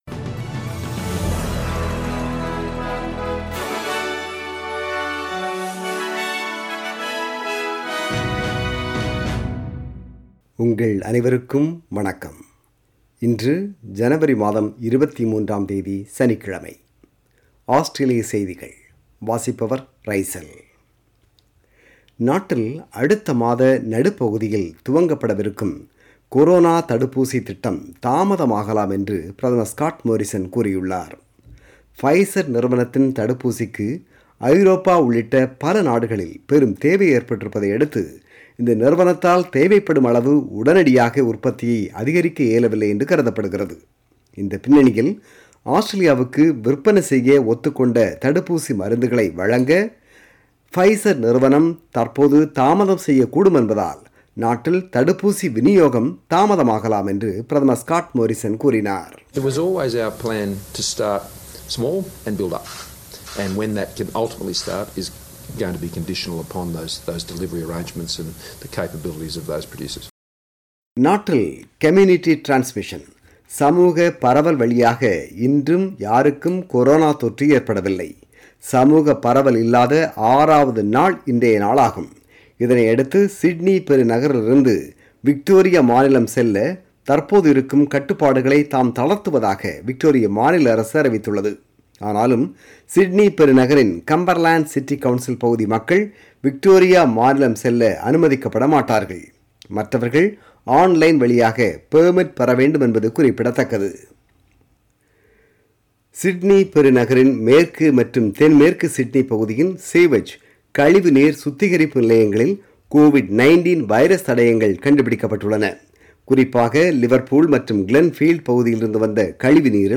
news_23_jan.mp3